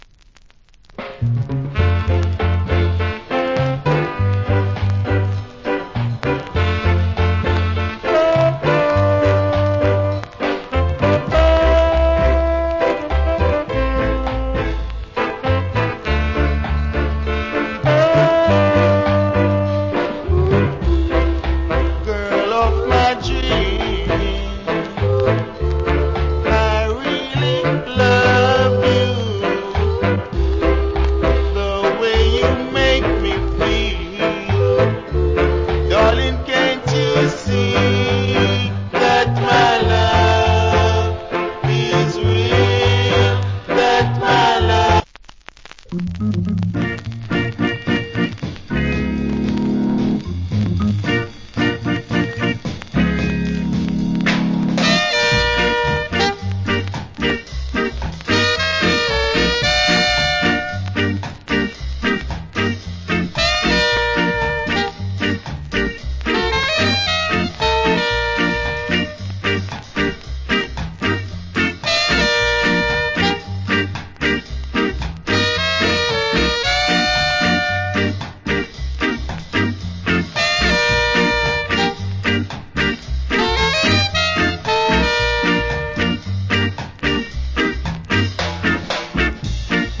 Nice Rock Steady Vocal.